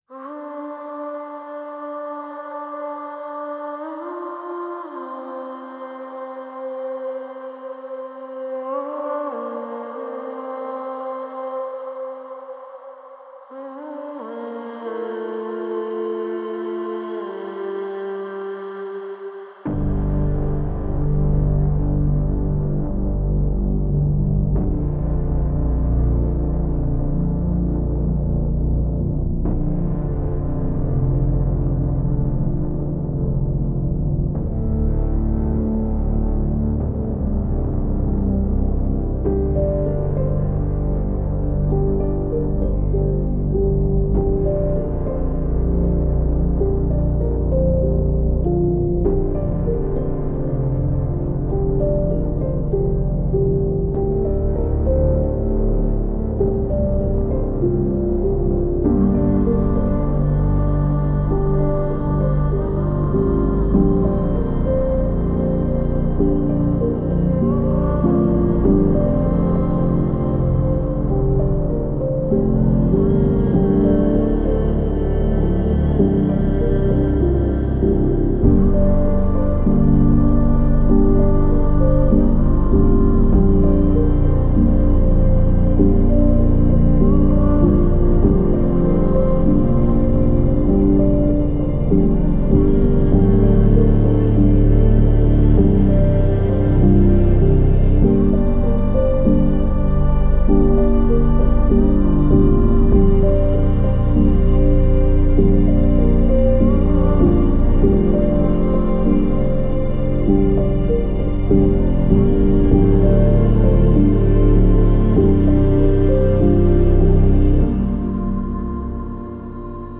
Melancholic female vocal textures and subtle piano.